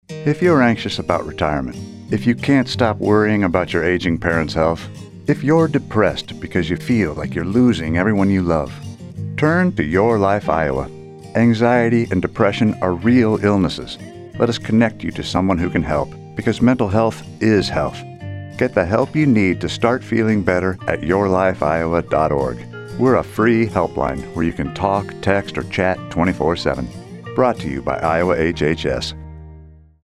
:30 Radio | Let Us Help You | (Male Voice 2)
Radio spot :30 Radio | Let Us Help You | (Male Voice 2) This campaign promotes older adults to reach out for help when experiencing anxiety, depression, and other mental health symptoms.
YLI Adult Mental Health Radio Spot Male 2_0.mp3